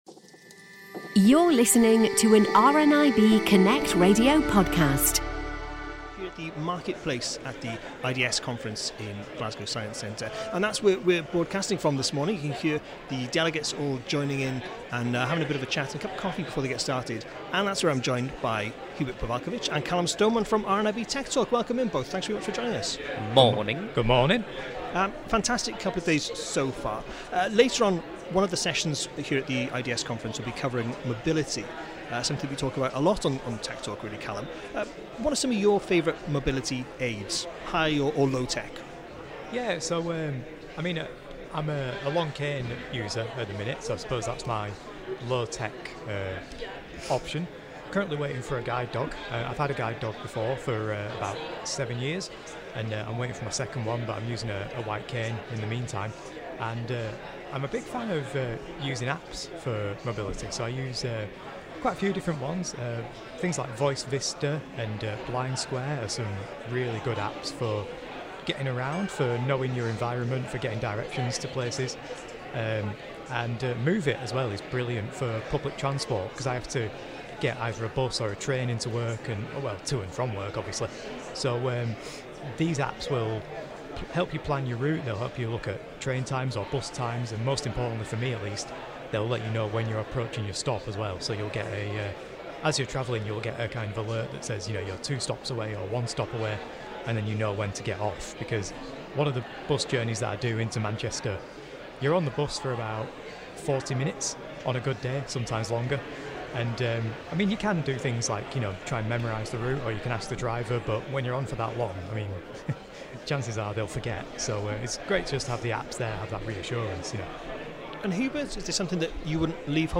On Tuesday 18th of March, The Daily Connect show was broadcasting live from the Inclusive Design for Sustainability Conference in Glasgow.